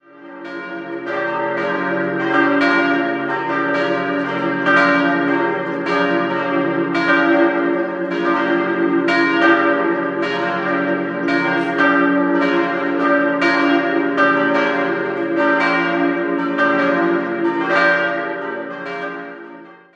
Idealquartett: d'-f'-g'-b' Die drei großen Glocken wurden 1950 von Karl Czudnochowksy in Erding gegossen, die kleine stammt von Ulrich Kortler (München) und wurde im Jahr 1913 hergestellt.